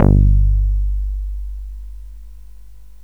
enkel bas.wav